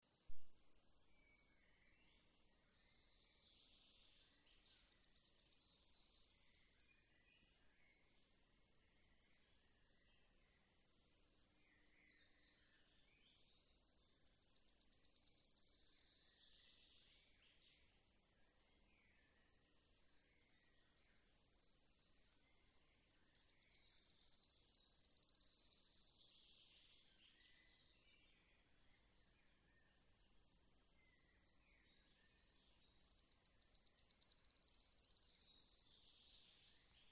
Putni -> Ķauķi ->
Svirlītis, Phylloscopus sibilatrix
StatussDzied ligzdošanai piemērotā biotopā (D)